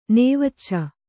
♪ nēvacca